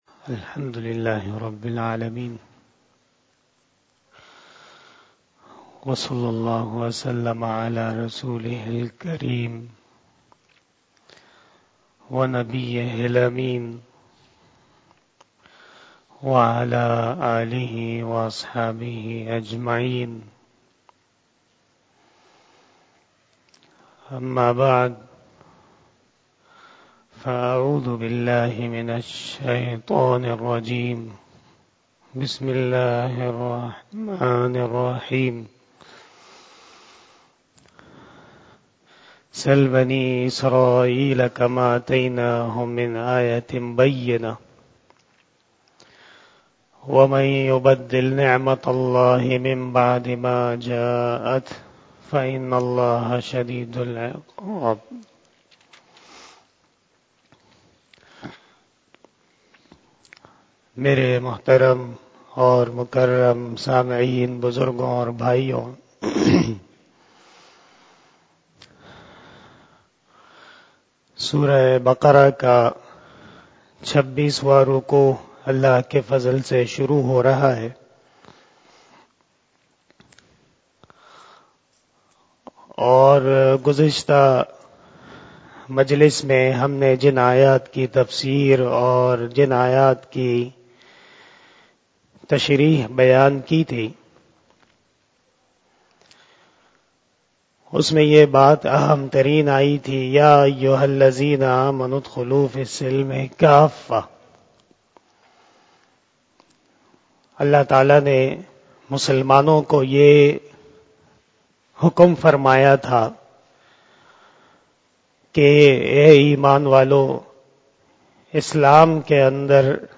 بیان شب جمعۃا لمبارک